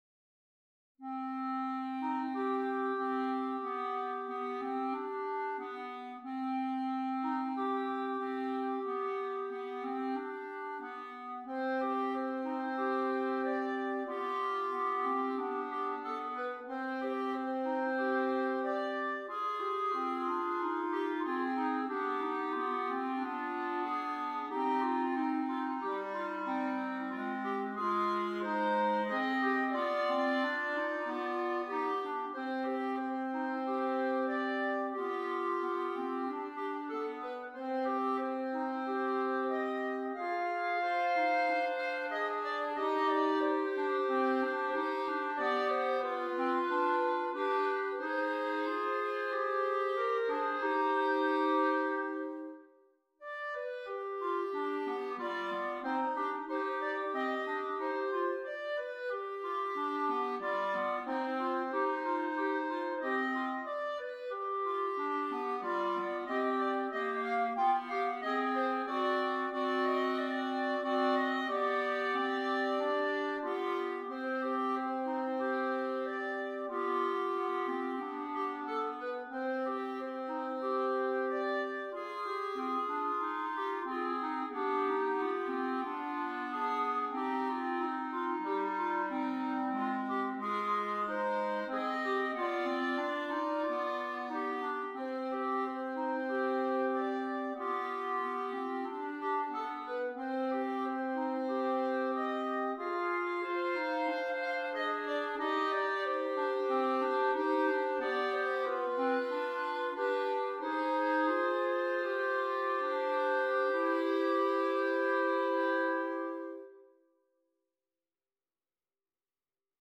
3 Clarinets